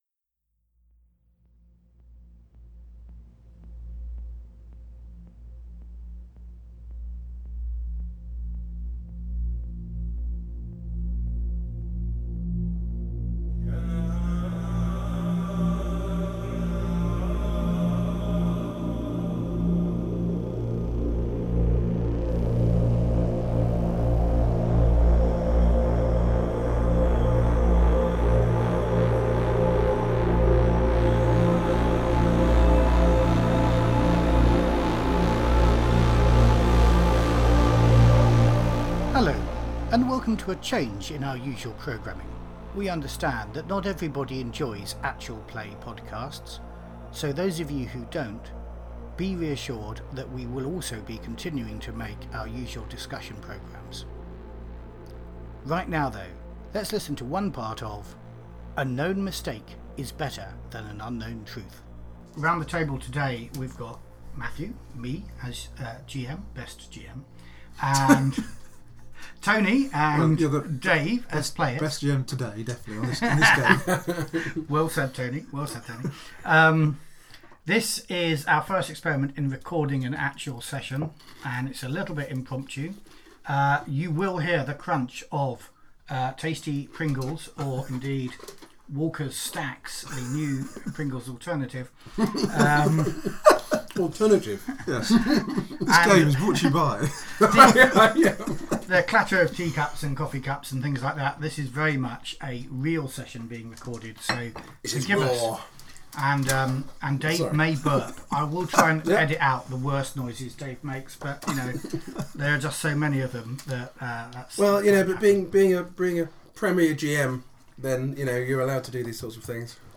The first hour of our Actual Play. Yaphet and Salah wake up in the the dark.